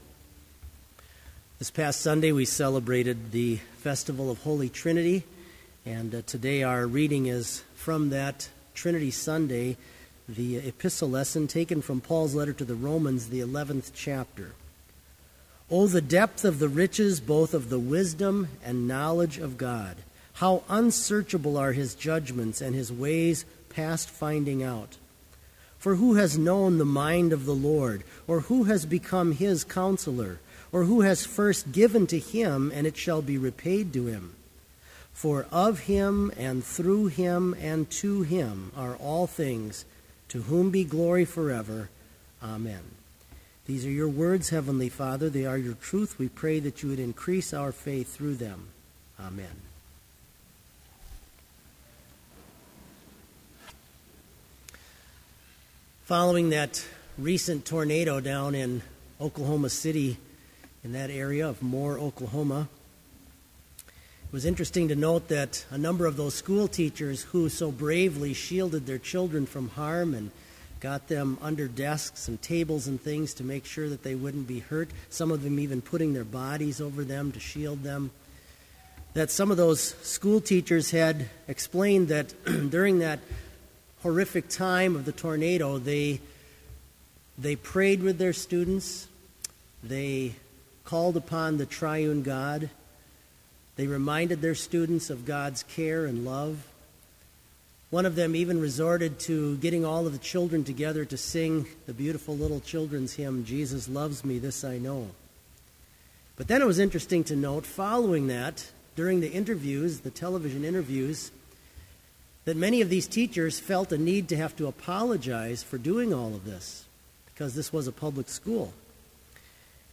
Sermon audio for Summer Chapel - May 29, 2013